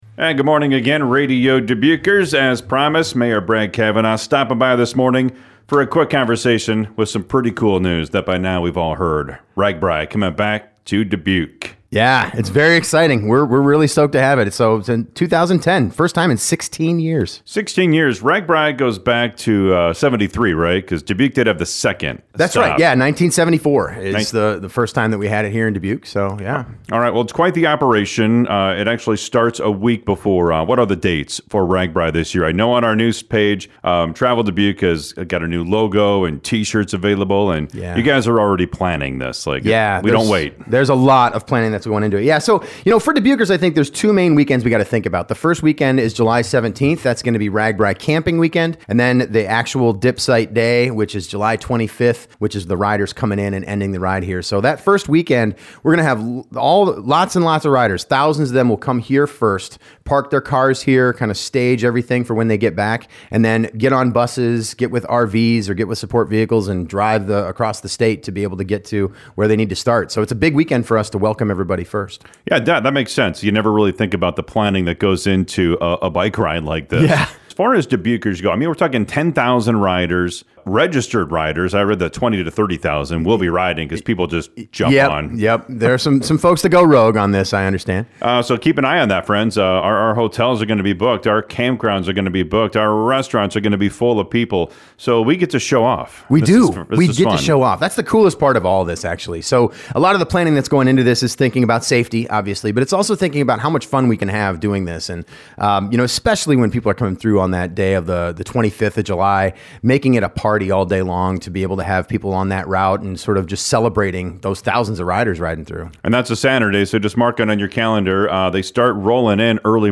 Listen to Radio Dubuque’s interview with Mayor Brad Cavanah here